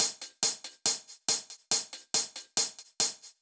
Index of /musicradar/french-house-chillout-samples/140bpm/Beats
FHC_BeatB_140-01_Hats.wav